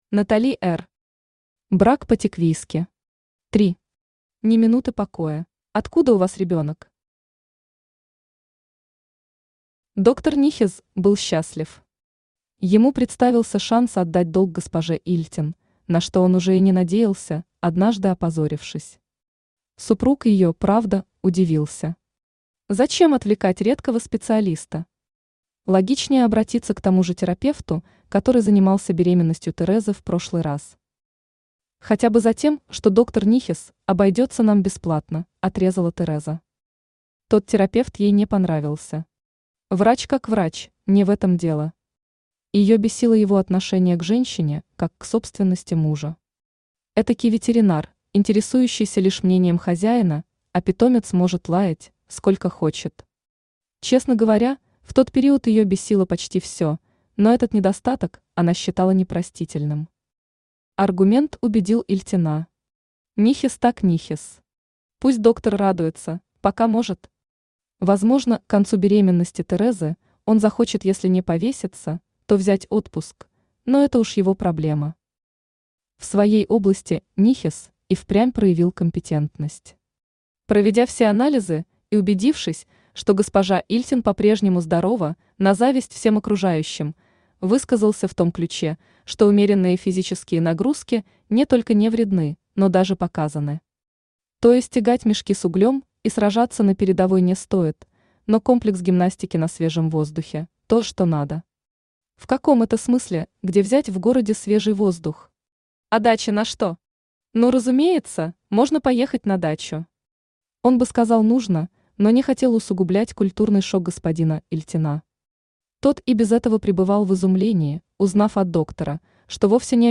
Ни минуты покоя Автор Натали Р. Читает аудиокнигу Авточтец ЛитРес.